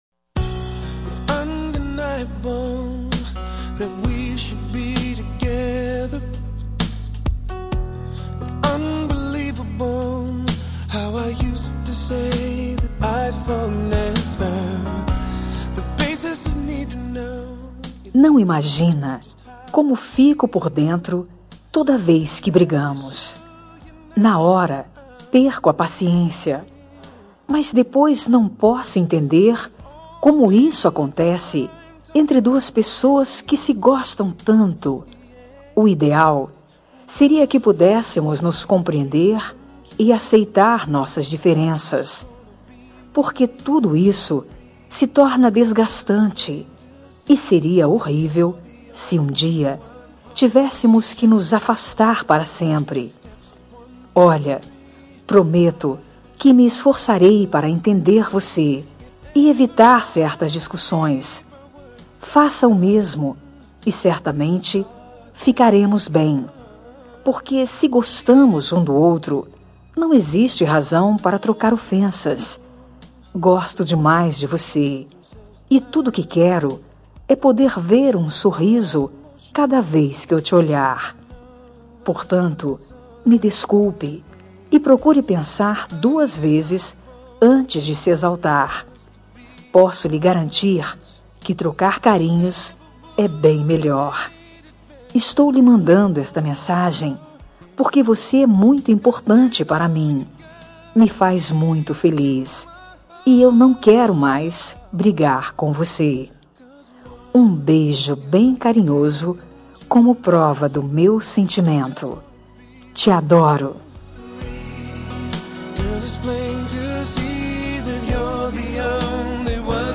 Telemensagem de Desculpas – Voz Feminina – Cód: 362 Linda